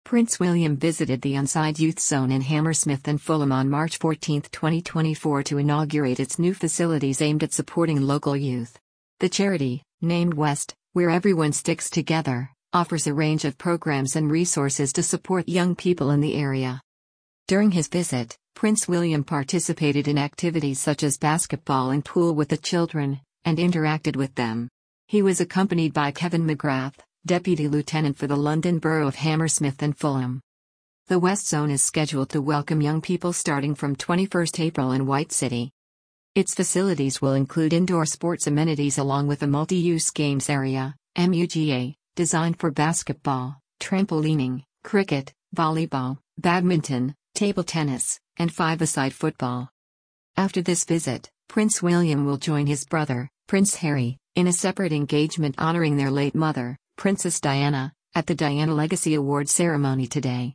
Listen to this article powered by AI.